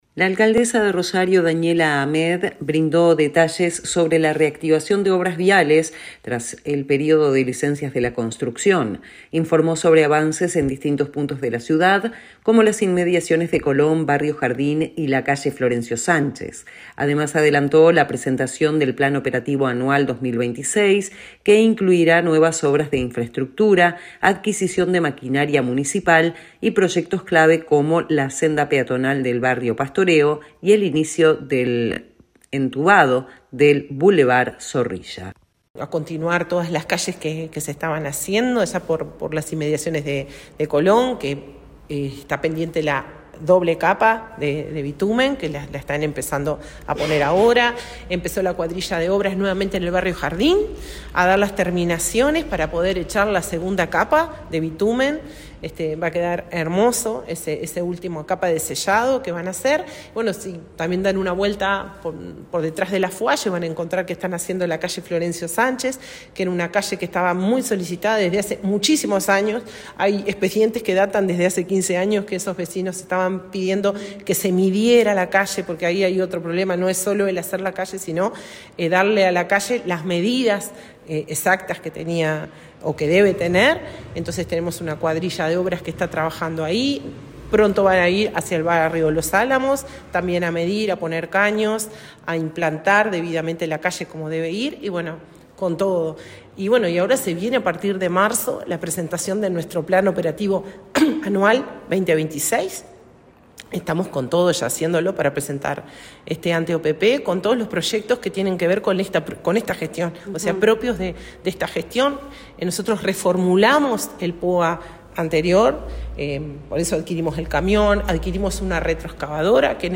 La alcaldesa de Rosario, Daniela Amed, brindó detalles sobre la reactivación de obras viales tras el período de licencias de la construcción. Informó sobre avances en distintos puntos de la ciudad, como las inmediaciones de Colón, barrio Jardín y la calle Florencio Sánchez. Además, adelantó la presentación del Plan Operativo Anual 2026, que incluirá nuevas obras de infraestructura, adquisición de maquinaria municipal y proyectos clave como la senda peatonal del barrio Pastoreo y el inicio del entubado del bulevar Zorrilla.